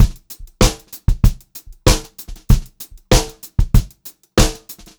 96POPBEAT4-L.wav